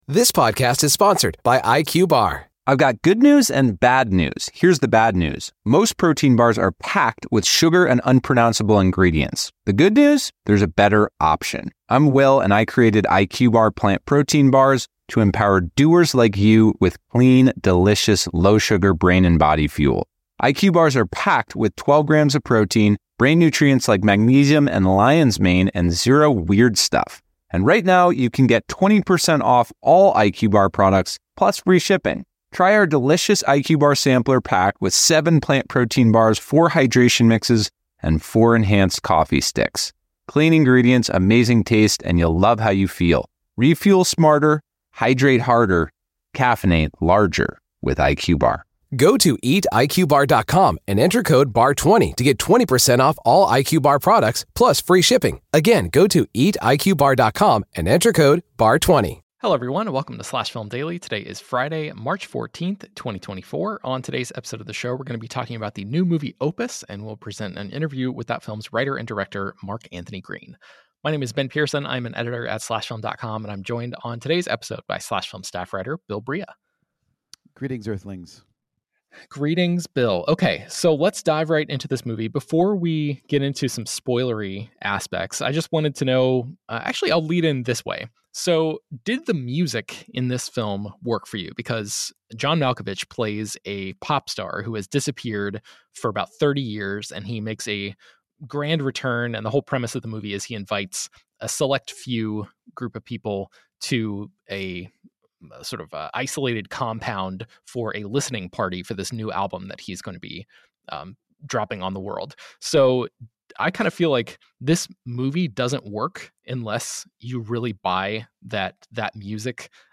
Entertainment News, News, Film Reviews, Tv Reviews, Tv & Film